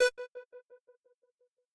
synth1_19.ogg